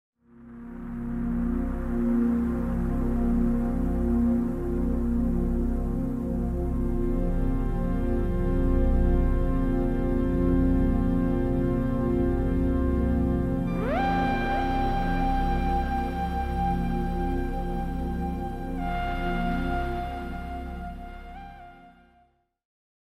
🔊 Dolby Atmos mix for sound effects free download
🔊 Dolby Atmos mix for an indie film